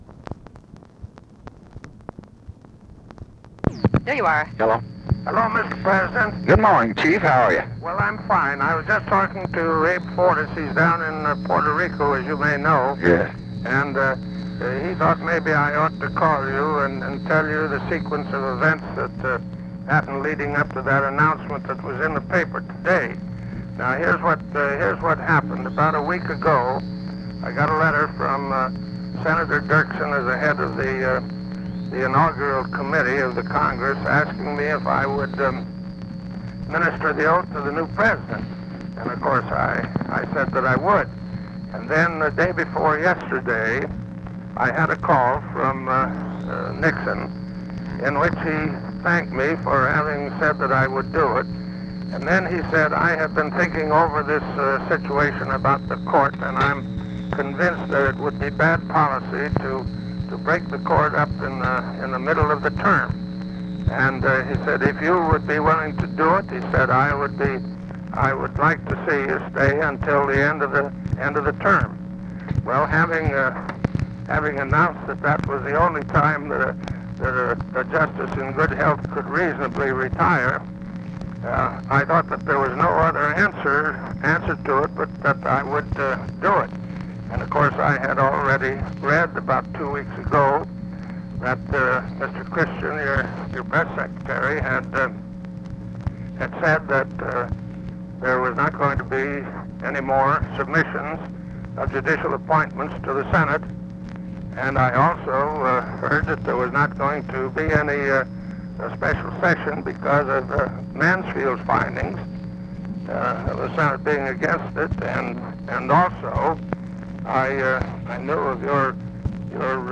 Conversation with EARL WARREN, December 5, 1968
Secret White House Tapes